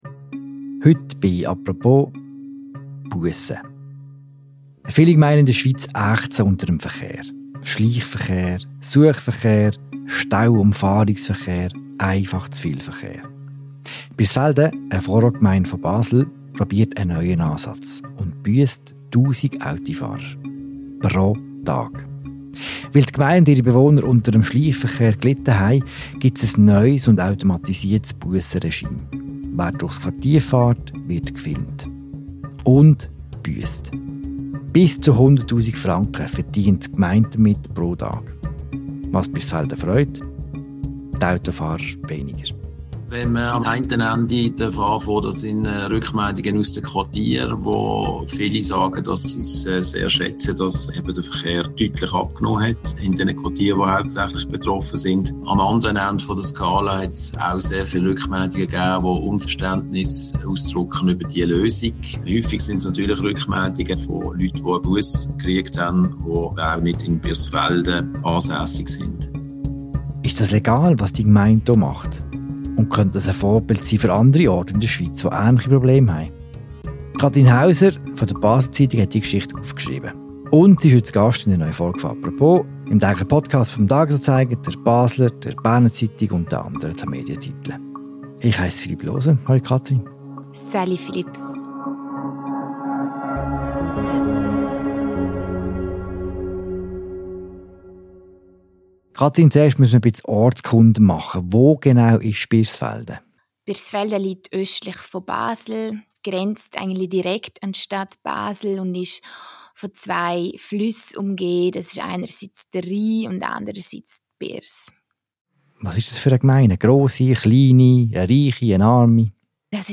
Viele Gemeinden in der Schweiz leiden unter dem täglichen Verkehr auf den Strassen. Eine Reportage aus einer von Blechlawinen überrollten Basler Ortsgemeinde.